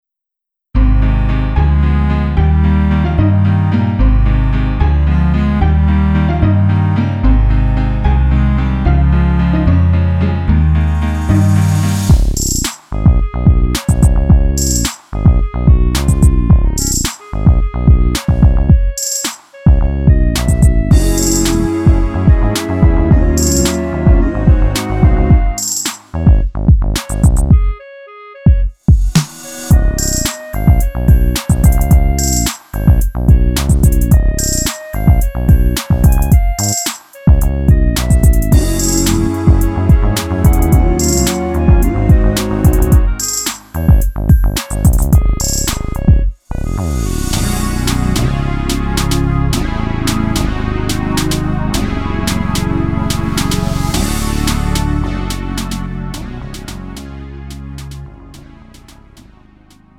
음정 -1키 3:11
장르 가요 구분 Lite MR